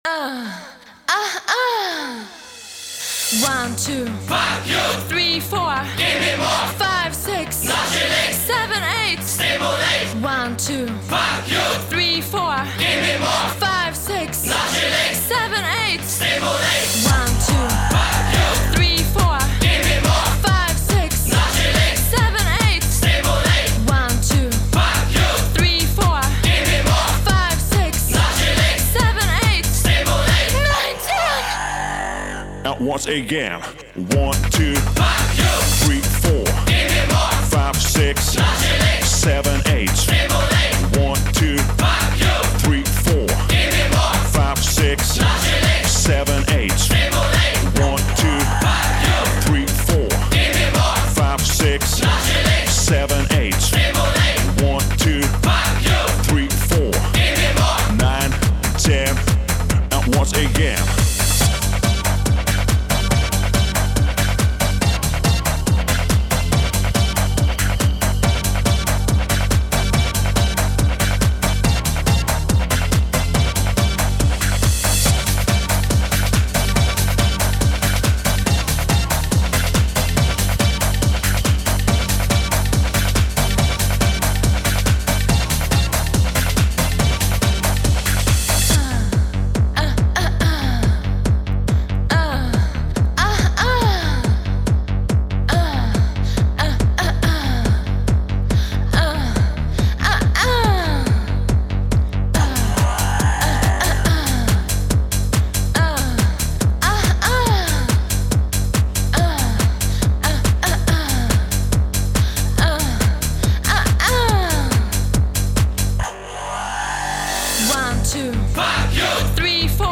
Genre: Pop.